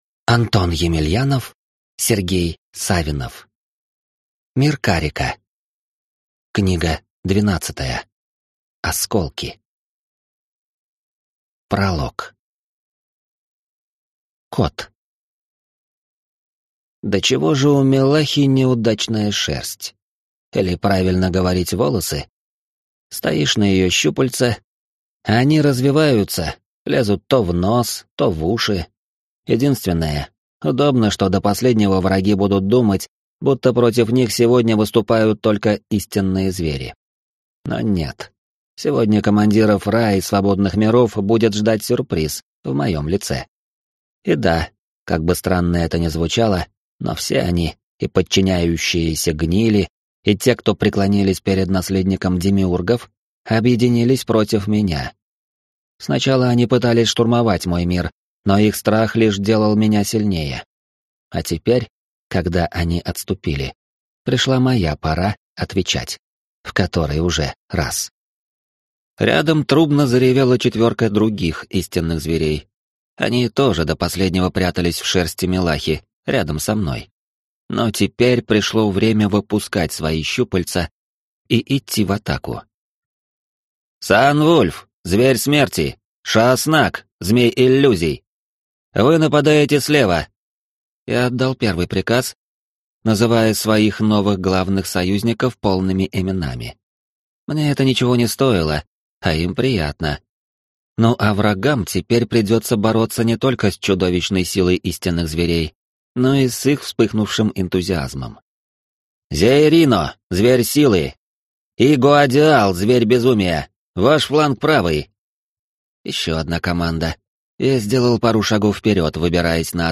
Аудиокнига Осколки | Библиотека аудиокниг